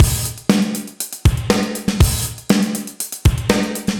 AM_GateDrums_120-01.wav